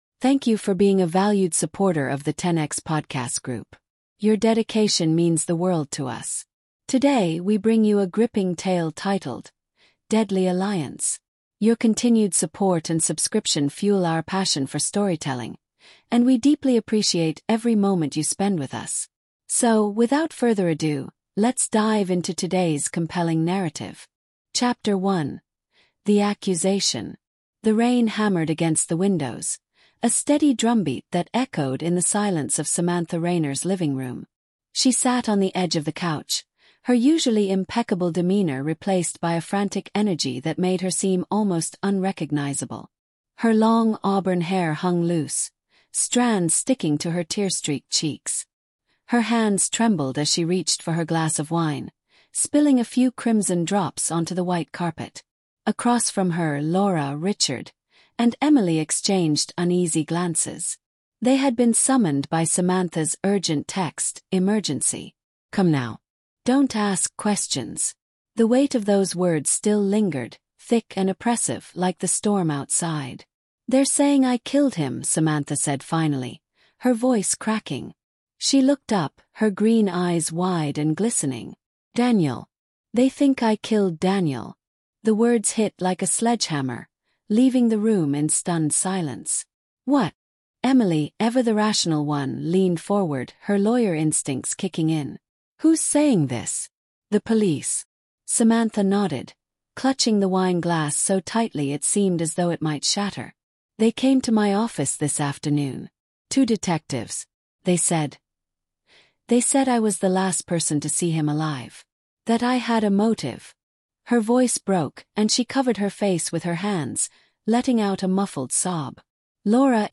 Deadly Alliance is a thrilling storytelling podcast that delves into the dark art of manipulation. Follow Samantha Rainer, a cunning woman who convinces her closest friends to lie for her after being accused of murder. But as cracks form in her carefully constructed web of deceit, the friends uncover disturbing truths, and the bonds of loyalty are tested to their breaking point.